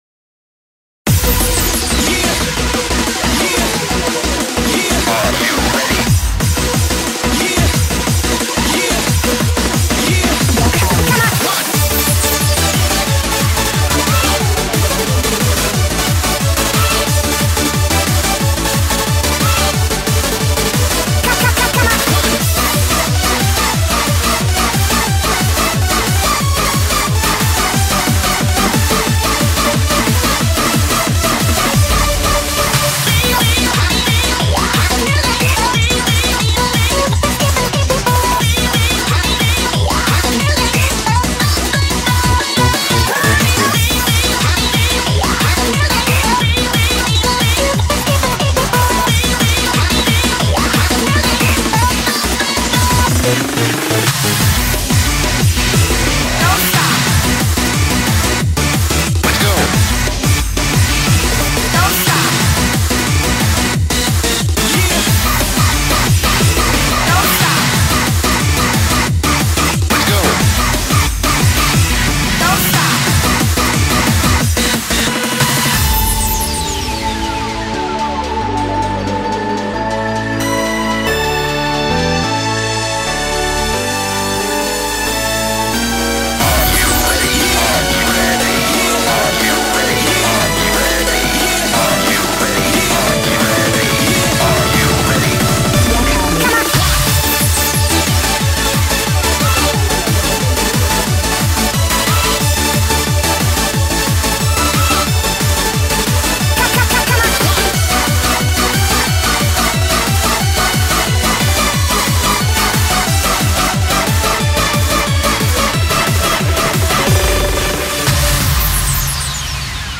BPM180
Audio QualityPerfect (Low Quality)